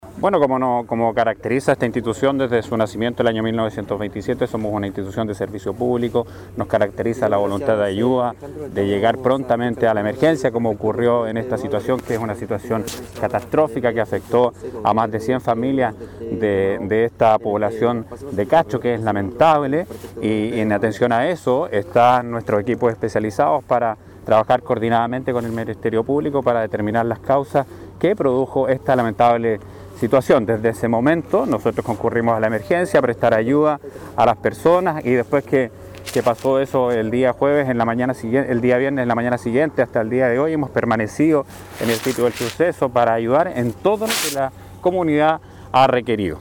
El coronel Pedro Álvarez, Prefecto de Chiloé, señaló que desde el primer instante de la emergencia, se encuentra Carabineros en disposición de aclarar lo antes posible cómo ocurrió este suceso.